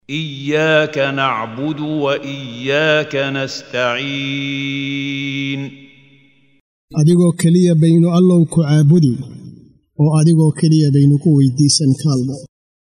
Waa Akhrin Codeed Af Soomaali ah ee Macaanida Suuradda Al-Fatihah ( Furitaanka ) oo u kala Qaybsan A